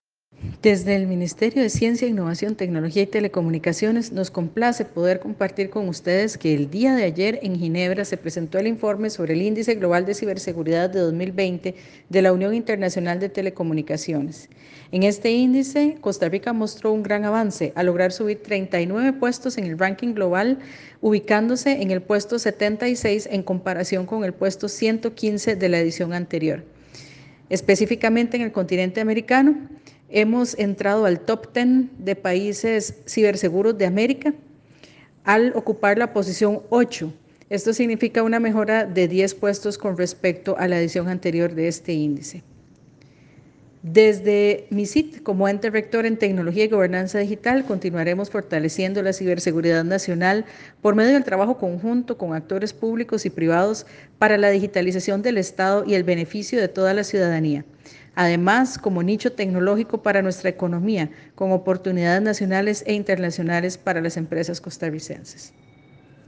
Declaraciones de la ministra Paola Vega Castillo sobre la mejora en índice Global de Ciberseguridad de Costa Rica del año 2020